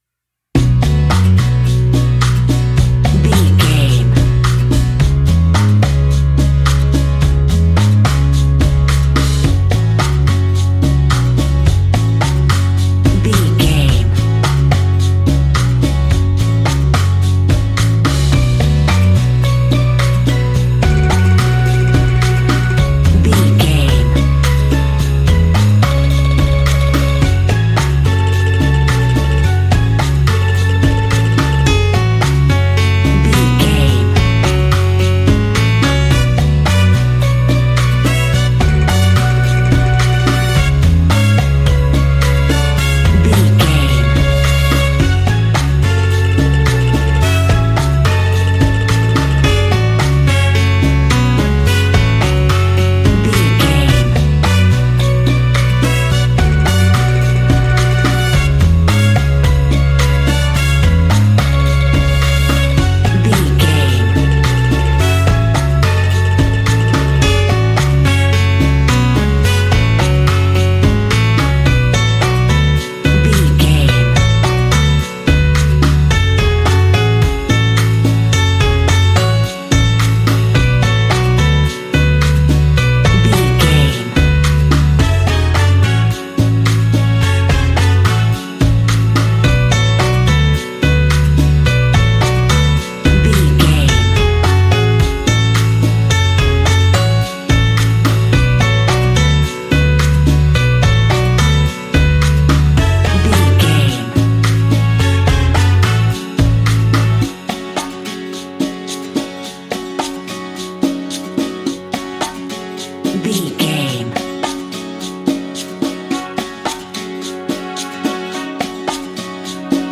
Aeolian/Minor
F#
calypso
steelpan
drums
percussion
bass
brass
guitar